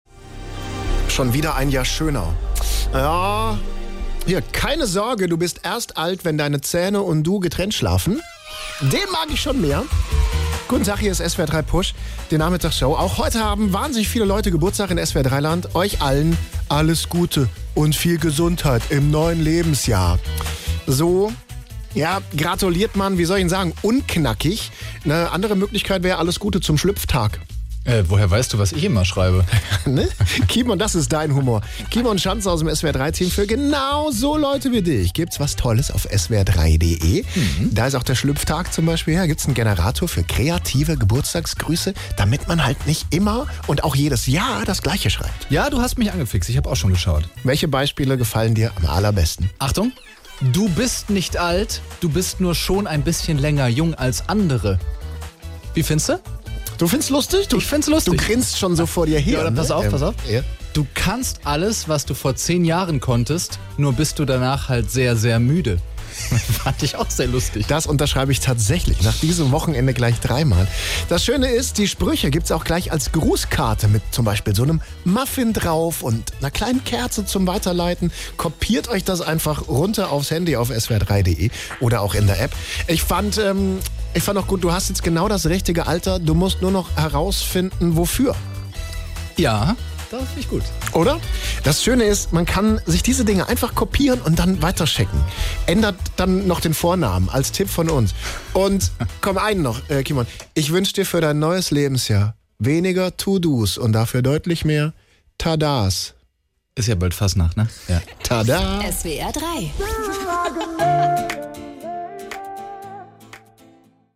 So lustig klingen die Whatsapp-Sprüche übrigens bei uns im Radio.